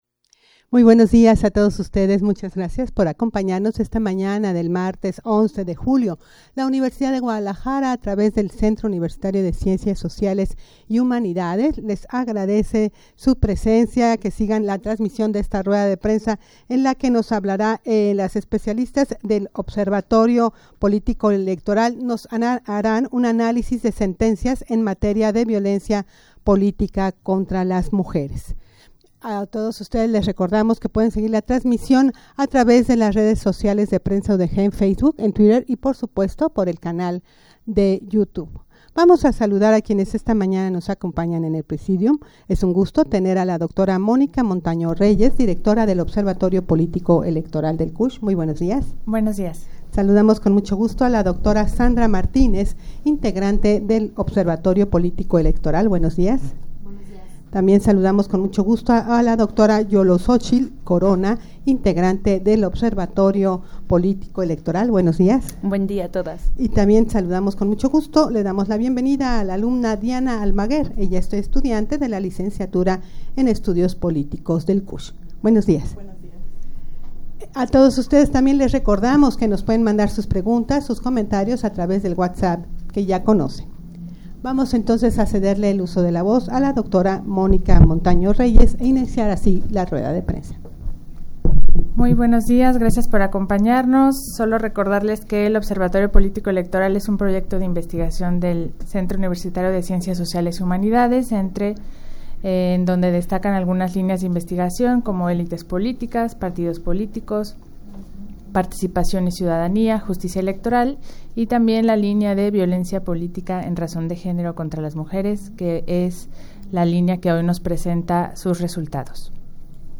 Audio de la Rueda de Prensa
rueda-de-prensa-analisis-de-sentencias-en-materia-de-violencia-politica-contra-mujeres.mp3